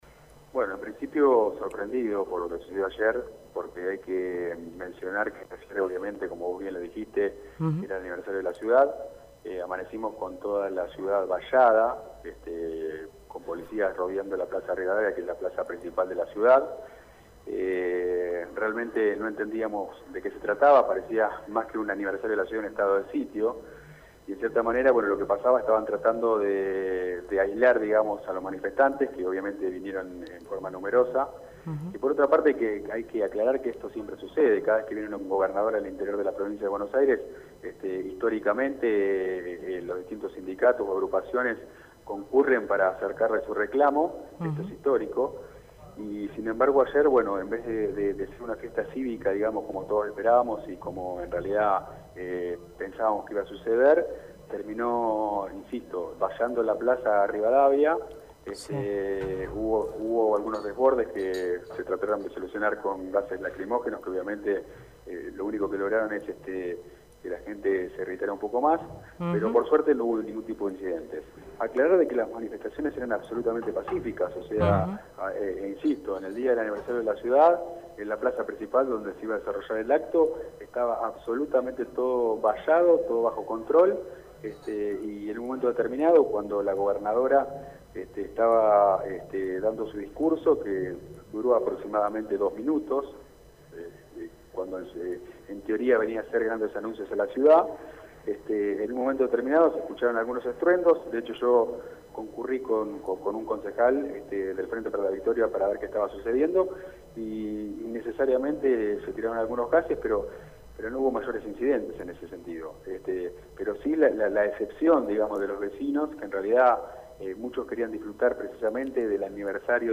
En diálogo con Clase Turista Rosenfelt se mostró sorprendido por lo sucedido: «Amanecimos con toda la ciudad vallada, con policías rodeando la plaza principal de la ciudad. Parecía más que un aniversario de la ciudad un estado de sitio».